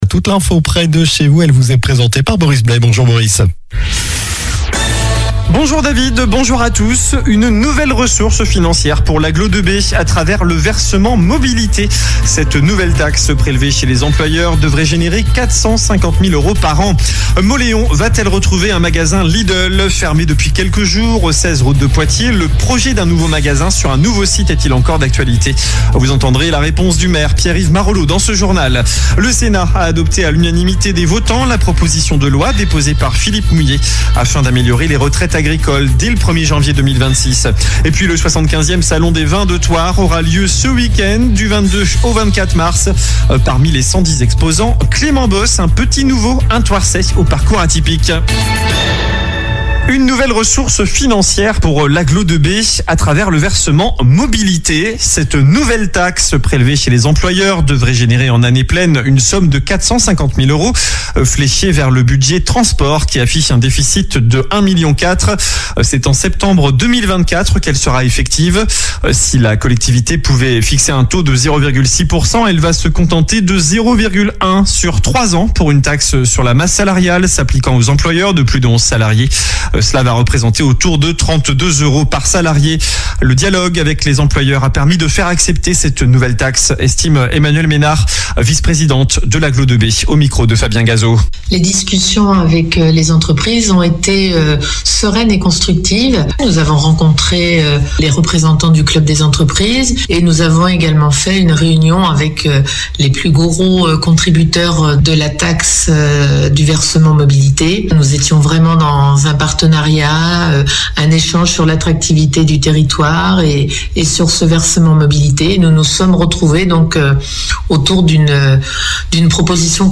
COLLINES LA RADIO : Réécoutez les flash infos et les différentes chroniques de votre radio⬦
Journal du mercredi 20 mars (midi)